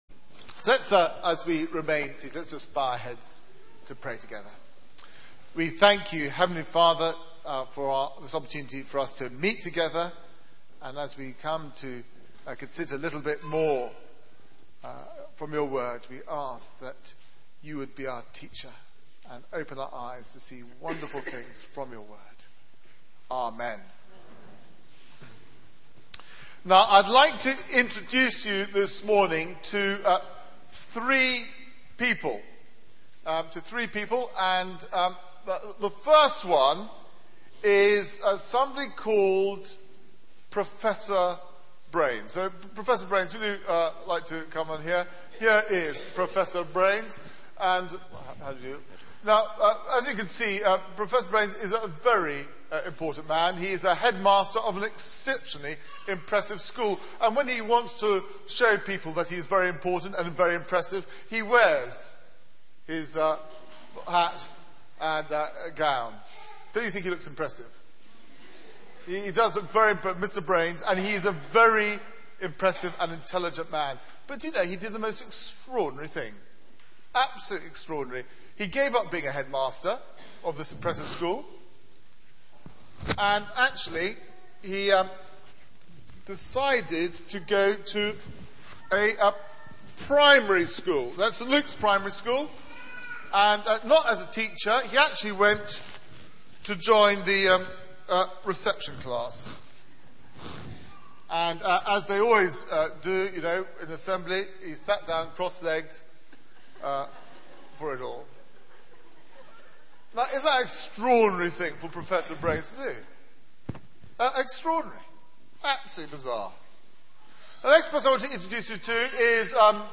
Media for 9:15am Service on Sun 19th Dec 2010 09:15 Speaker
Sermon